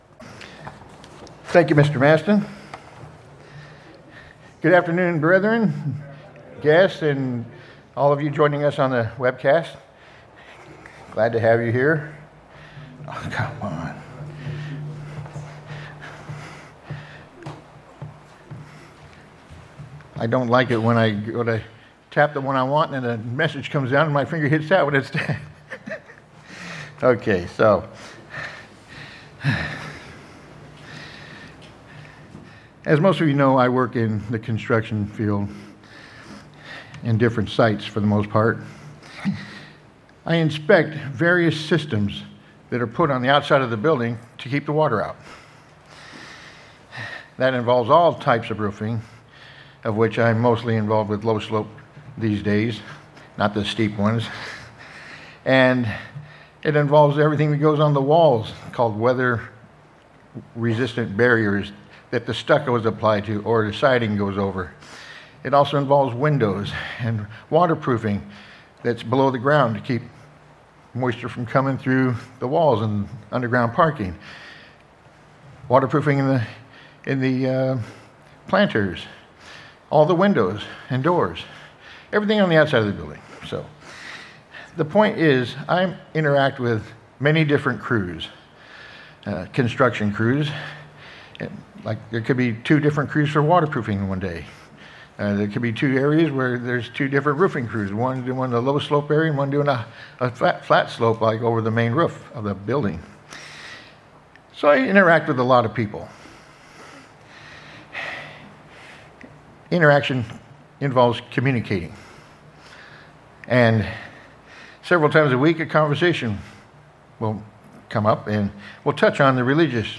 Given in Orange County, CA